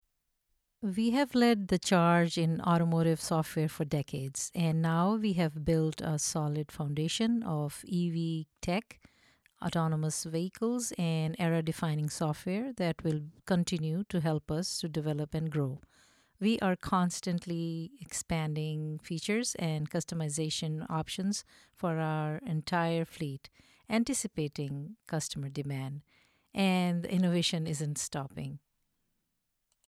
Explainer voice over for a video
The space is fully soundproofed to deliver clean, noise-free recordings.